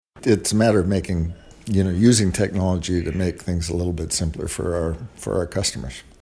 Mayor Ferguson says this funding could allow for the digital and electronic use of technology for rural and northern communities in the County that would mean residents no longer have to travel into Picton to access services, such as the purchase of a permit.